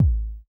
AIR Kick.wav